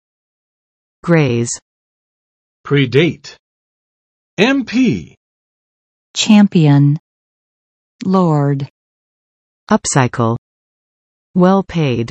[grez] v. 擦伤